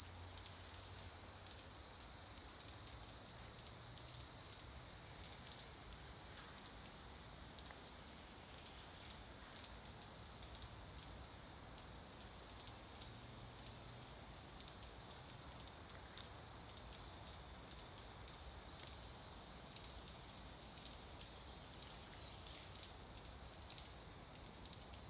蝉2017年7月
描述：蝉在美国乔治亚州，在炎热的夏夜。蟋蟀在背景中。
Tag: 蟋蟀 自然 现场录音 夏天 昆虫